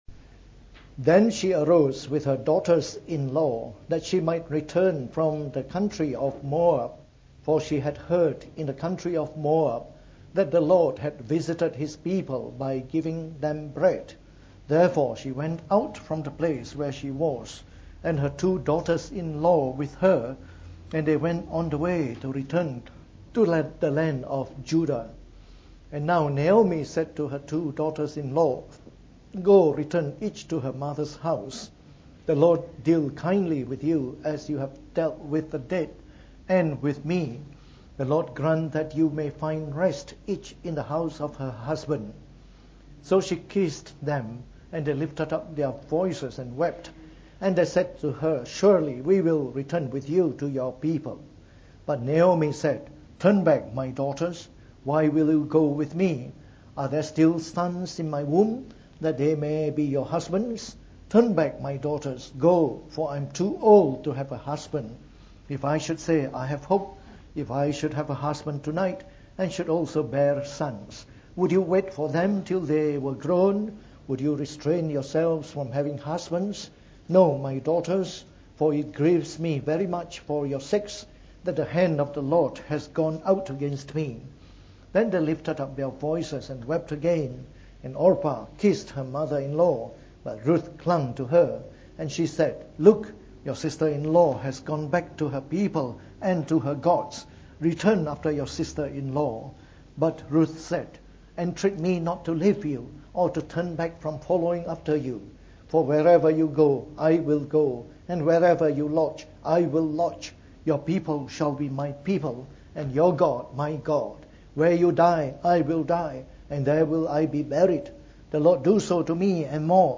Preached on the 4th of March 2018. From our series on the Book of Ruth delivered in the Morning Service.